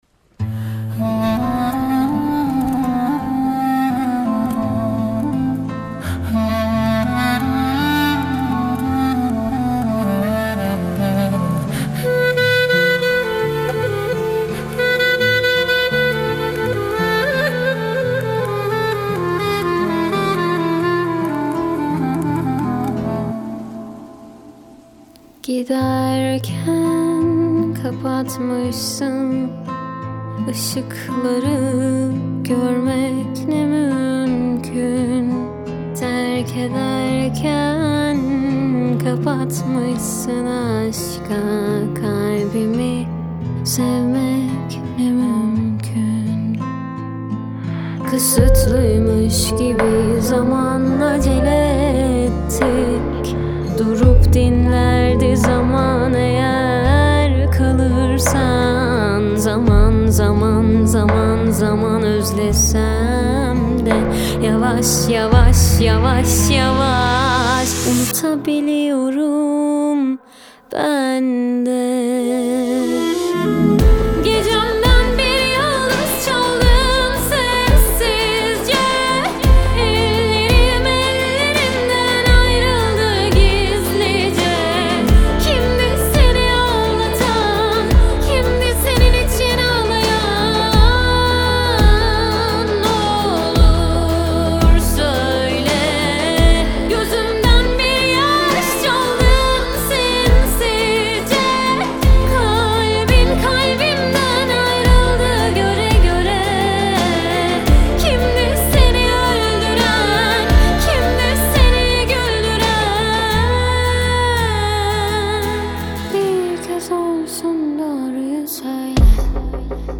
Трек размещён в разделе Турецкая музыка / Альтернатива.